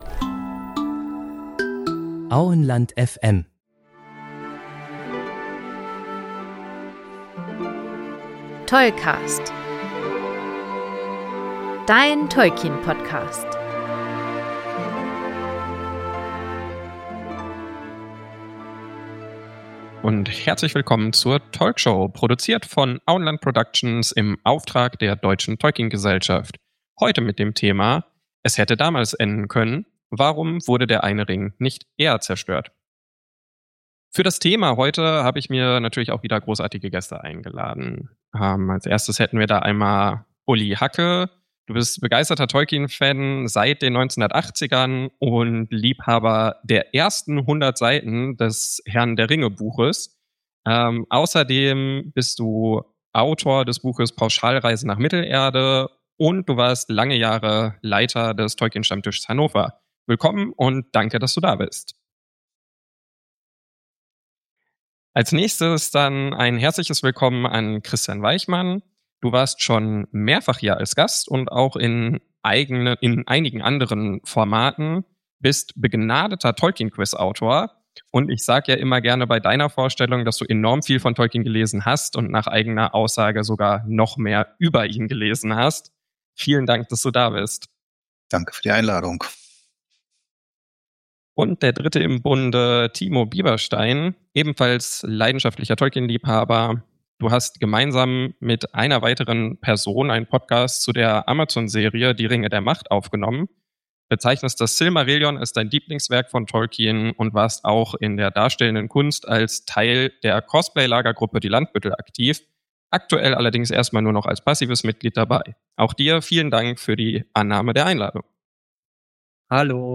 Beschreibung vor 1 Monat In dieser Talkshow wird die Frage verhandelt, warum der Eine Ring nicht schon viel früher vernichtet wurde.